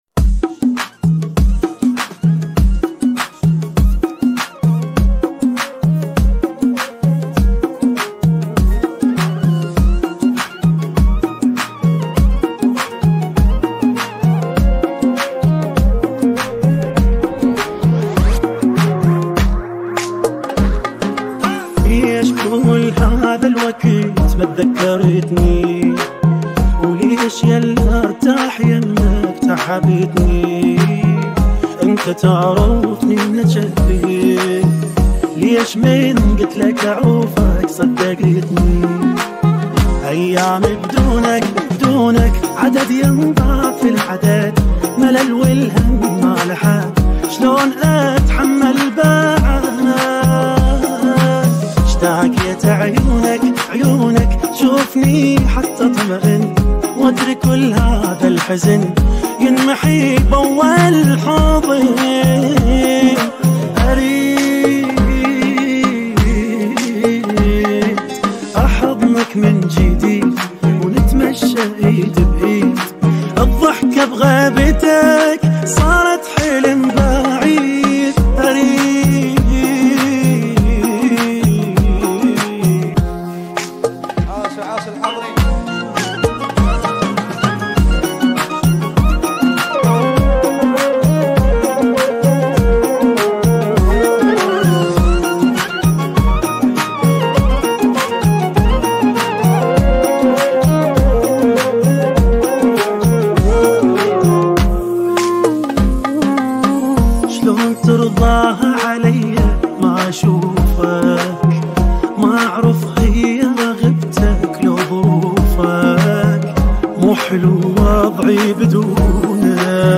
100 bpm